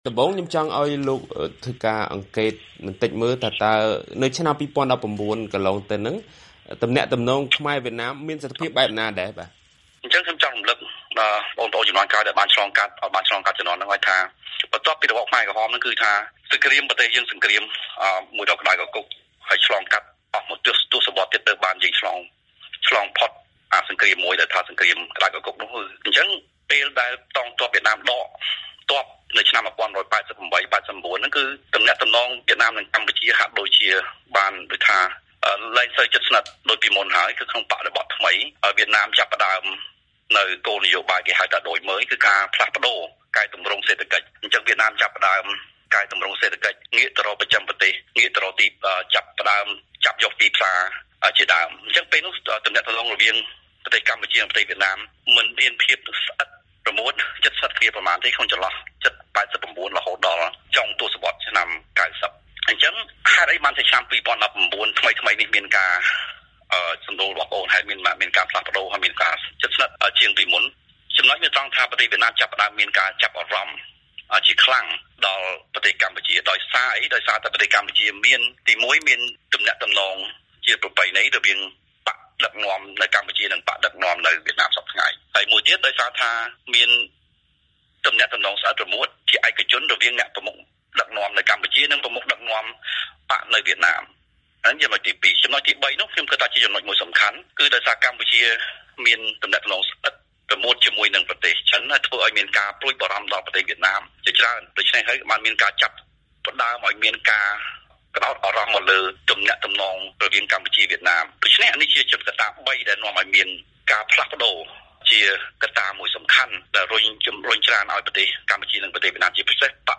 បទសម្ភាសន៍ VOA៖ វៀតណាមខ្លាចកម្ពុជាទៅរណបចិនហើយងាកមកប្រឆាំងនឹងខ្លួន